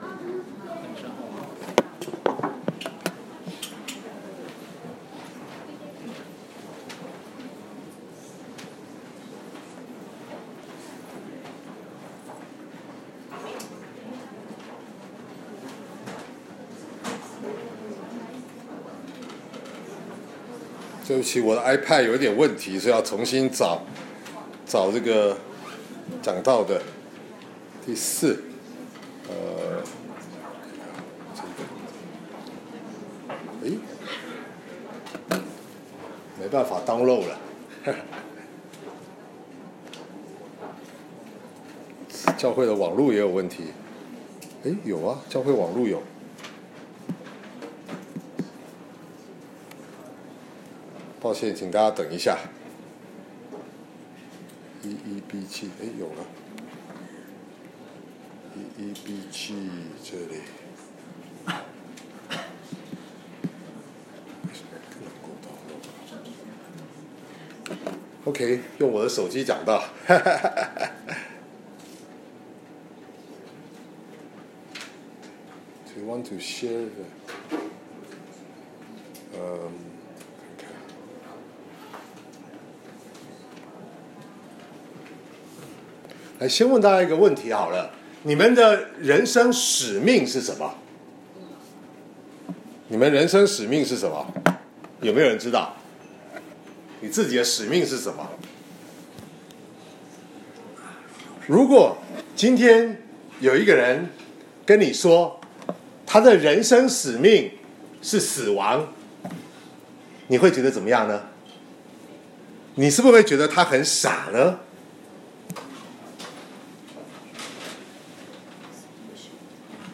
2019年4月7日主日讲道：【道成肉身的使命（四）】